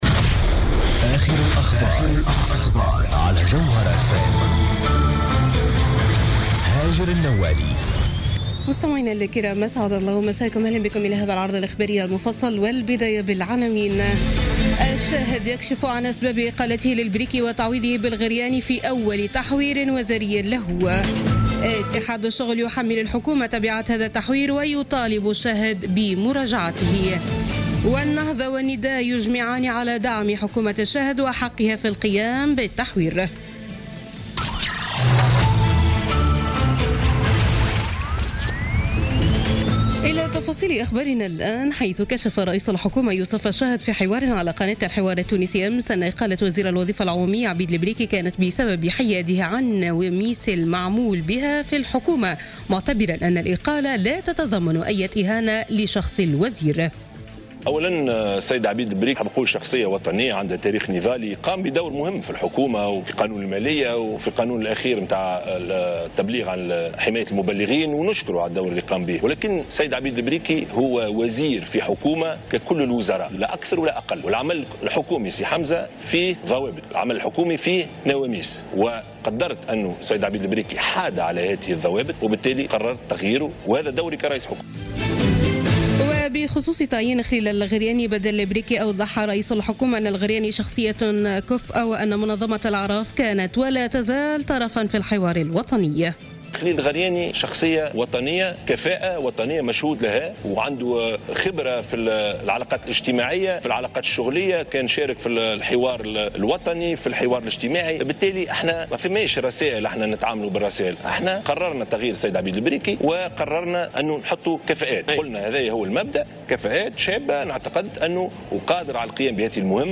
نشرة أخبار منتصف الليل ليوم الاثنين 27 فيفري 2017